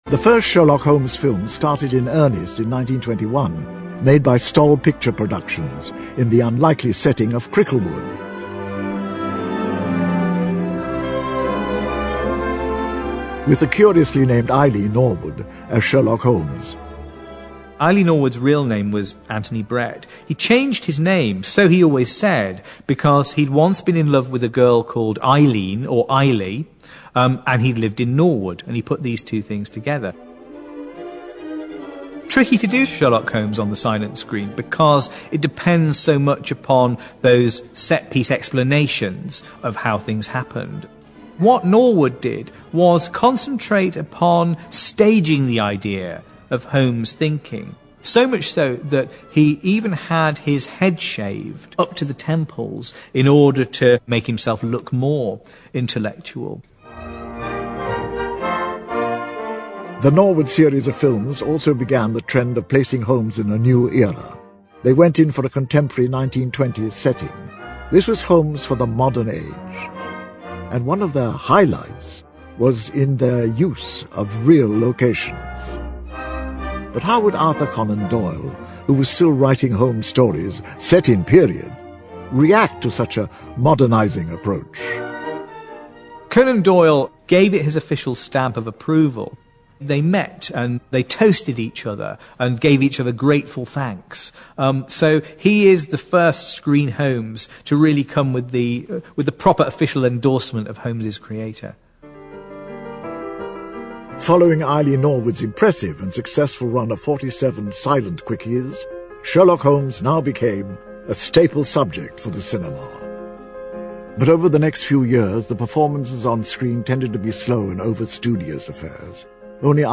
在线英语听力室如何成为福尔摩斯 第7期的听力文件下载, 《如何成为福尔摩斯》栏目收录了福尔摩斯的方法，通过地道纯正的英语发音，英语学习爱好者可以提高英语水平。